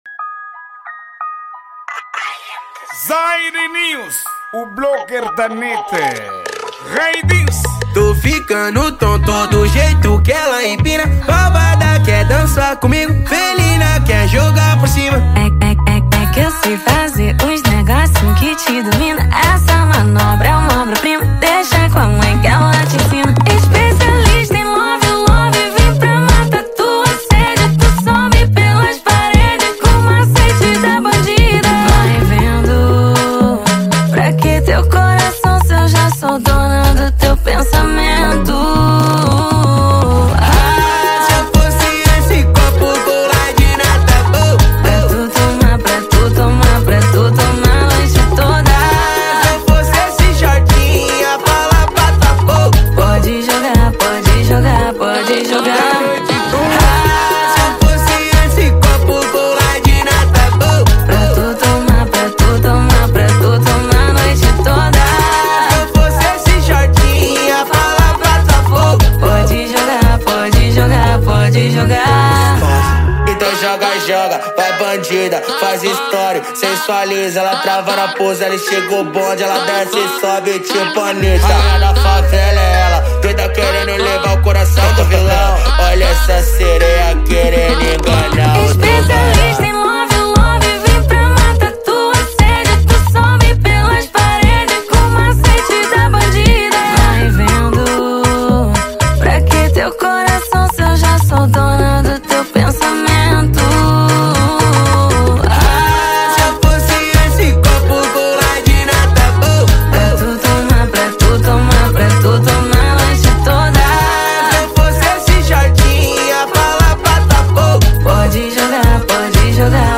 Gênero:Funk